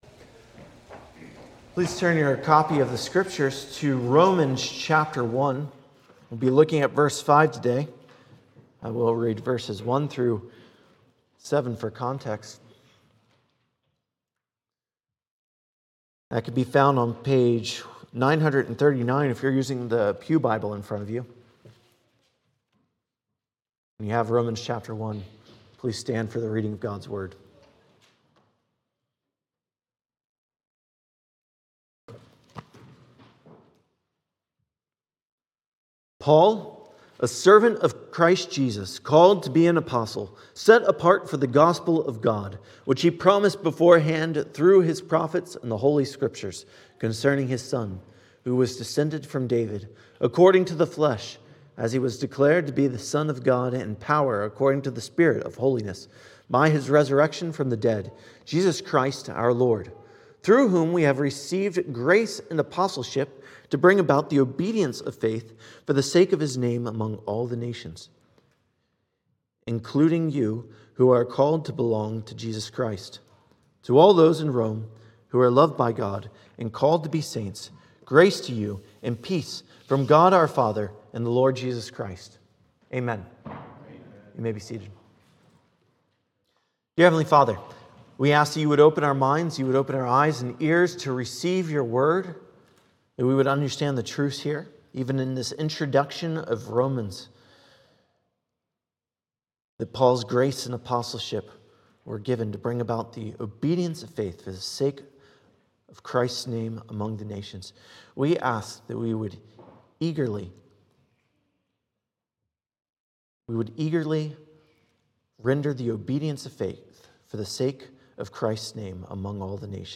Sermon: Good Works Advance the Name of Christ